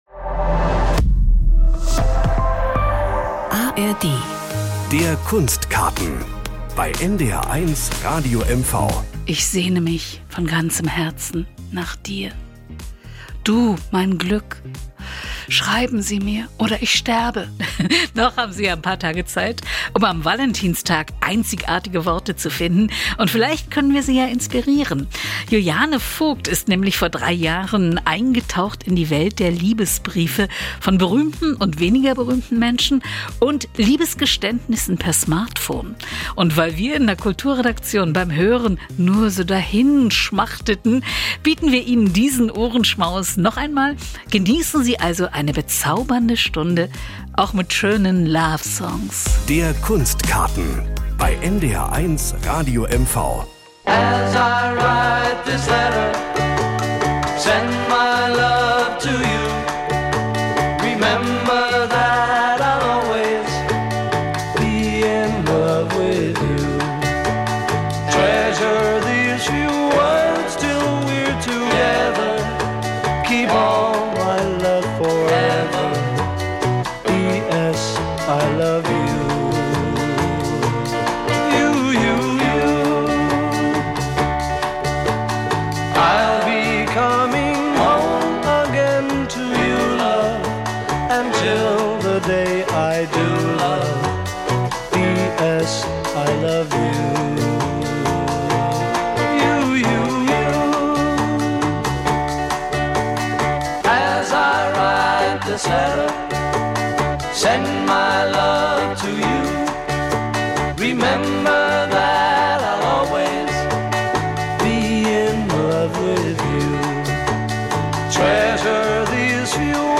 Ausstellungen, Konzerte, Theater, Literatur, Kunst oder Geschichte - im NDR 1 Radio MV Kunstkaten erleben Sie Künstler in Aktion und im Gespräch.